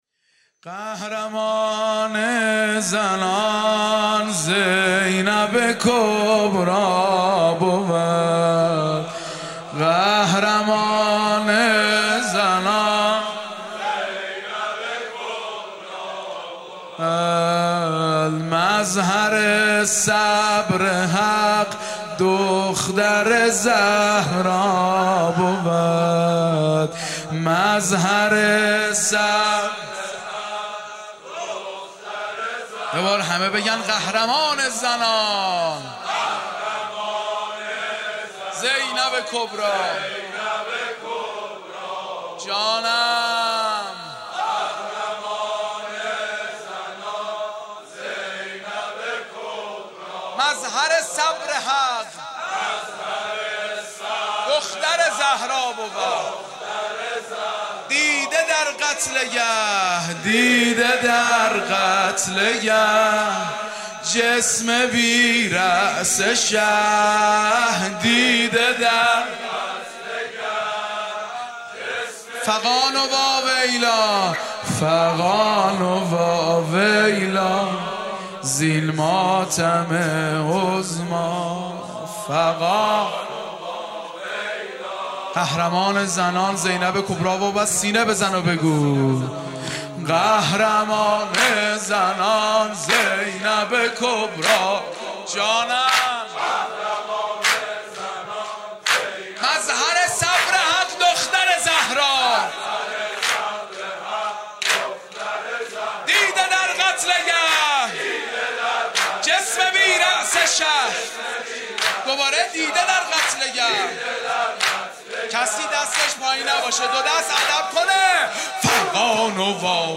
شب شهادت حضرت زینب(س)/هیات عبدالله بن الحسن(ع)